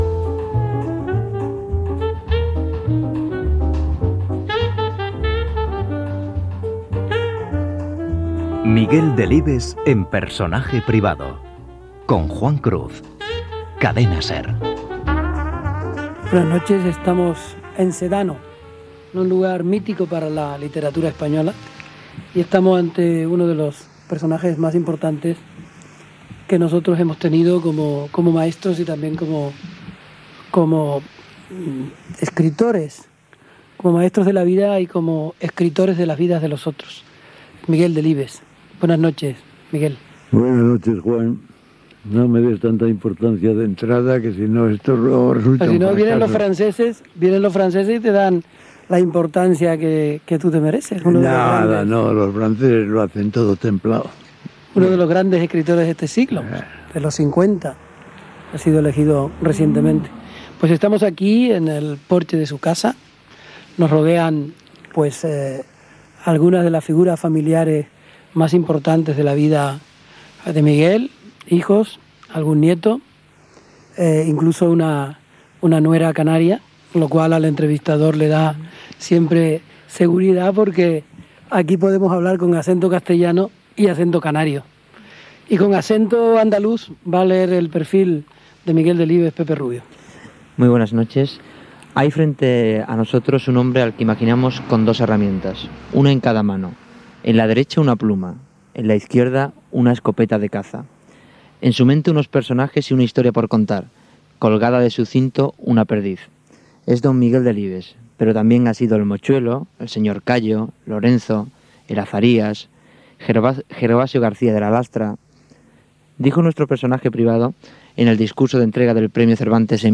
Careta, presentació, perfil biogràfic i entrevista a l'escriptor Miguel Delibes feta a casa seva